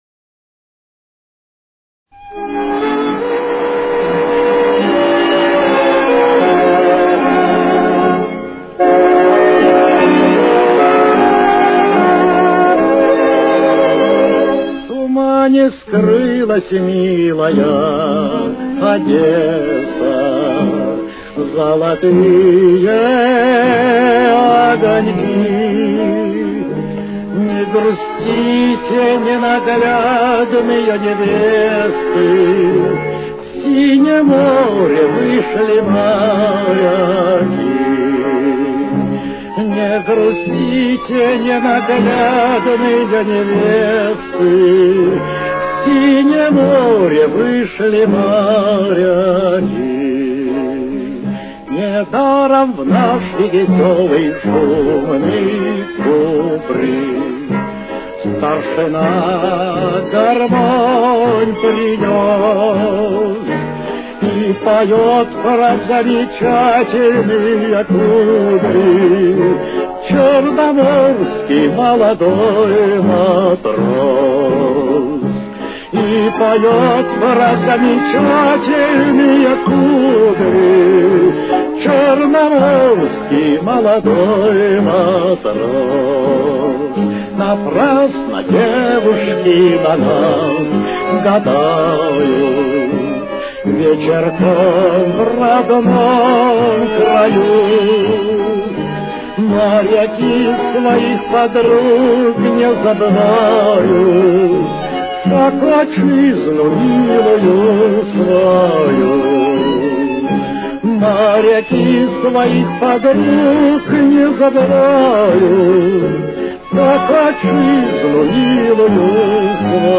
Темп: 80.